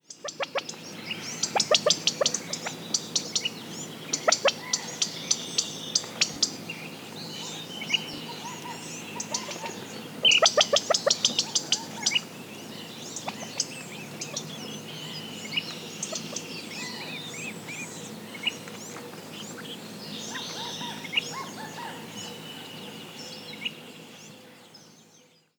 California Quail